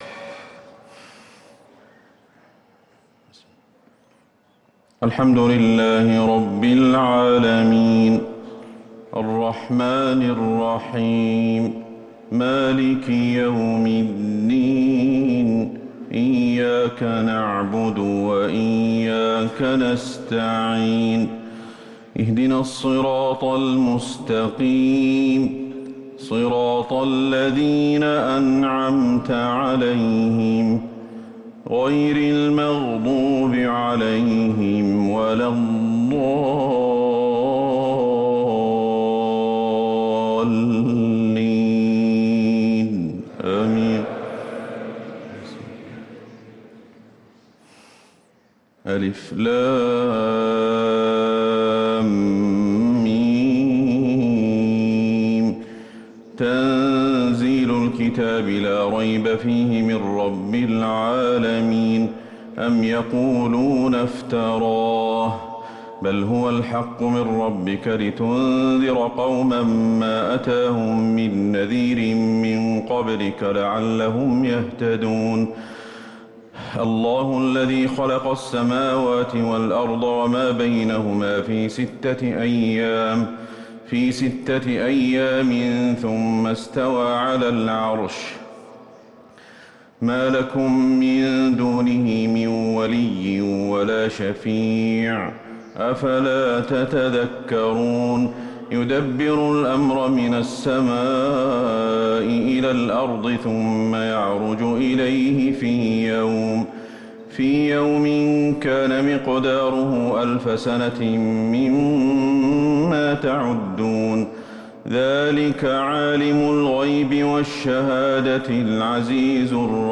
صلاة الفجر للقارئ أحمد الحذيفي 8 شوال 1444 هـ
تِلَاوَات الْحَرَمَيْن .